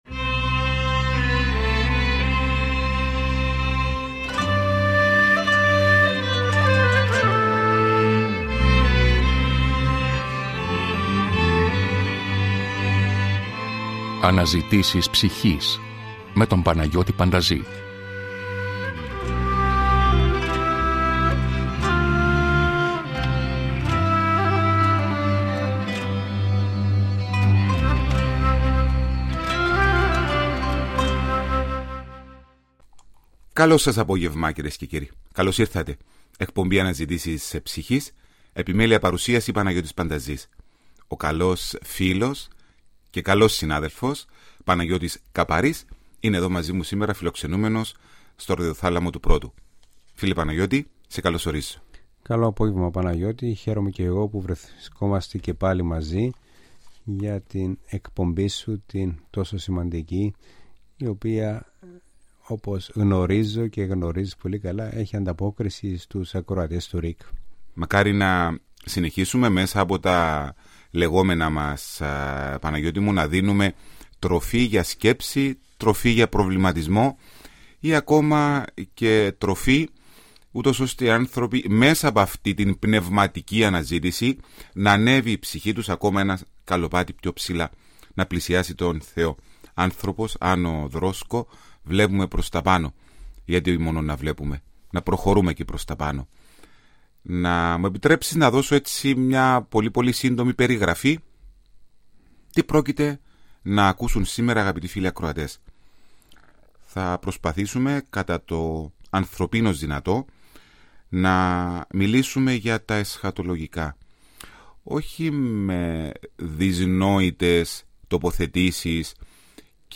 Φιλοξενούμενοι στο στούντιο, τοποθετούνται στους προβληματισμούς που αναπτύσσει ο σύγχρονος άνθρωπος πάνω σε διάφορα θρησκευτικά ζητήματα.